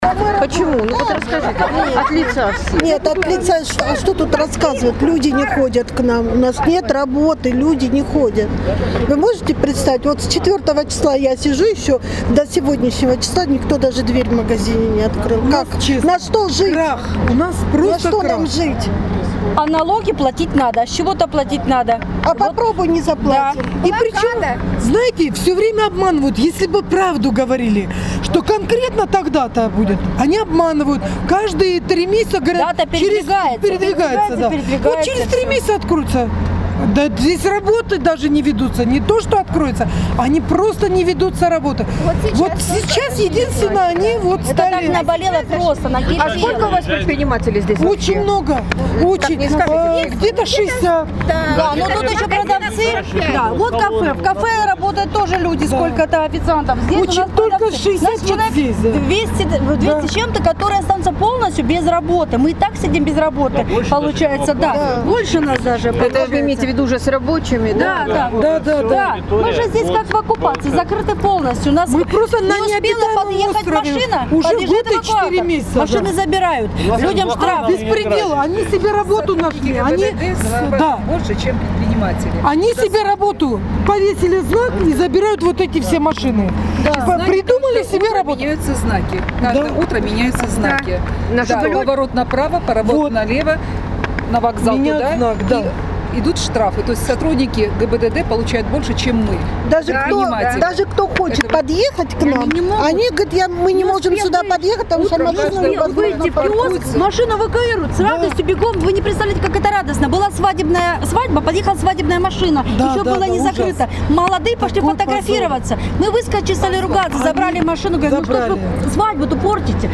А эта запись — настоящий крик души множества предпринимателей, для которых закрытие моста, лишившее их покупателей, стало настоящей катастрофой: